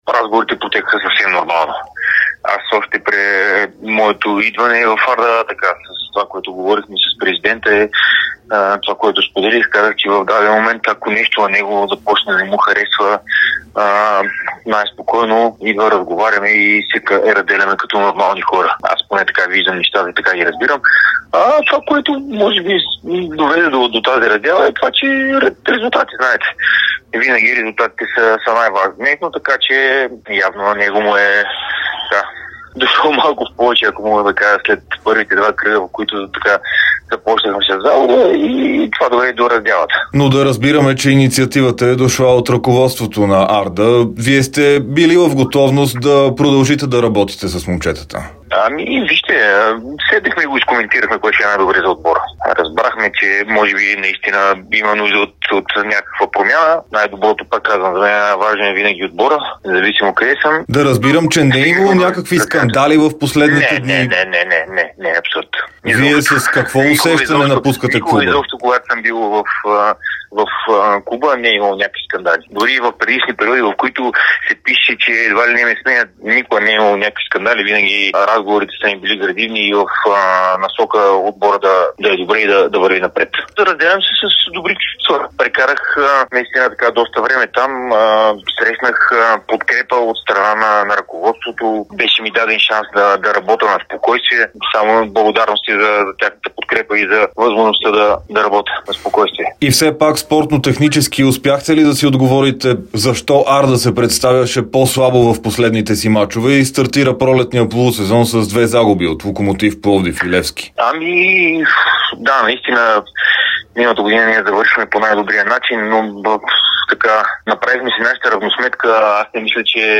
Бившият старши треньор на Арда Александър Тунчев даде специално интервю за Дарик радио и dsport. Той коментира престоя си в Кърджали и раздялата с отбора, както и сподели очакванията си за предстоящото гостуване срещу ЦСКА на Националния стадион „Васил Левски“ в мач от четвъртфиналите на Sesame Купа на България.